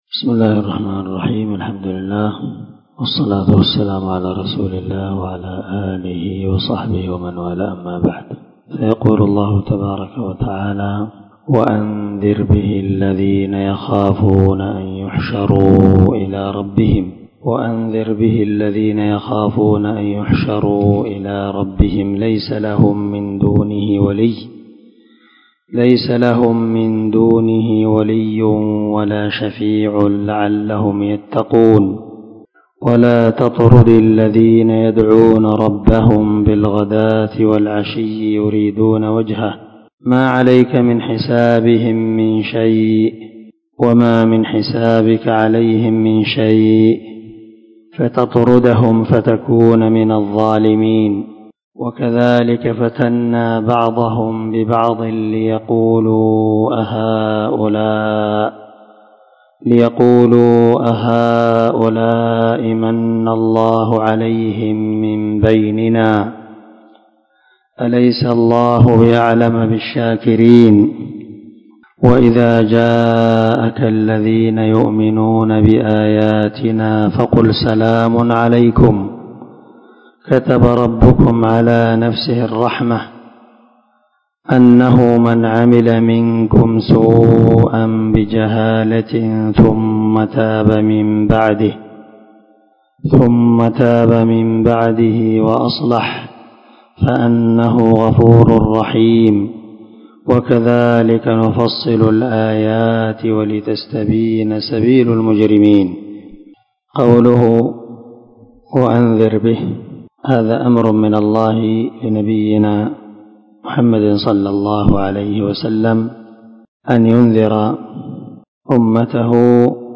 406الدرس 14 تفسير آية ( 51 – 55 ) من سورة الأنعام من تفسير القران الكريم مع قراءة لتفسير السعدي
دار الحديث- المَحاوِلة- الصبيحة.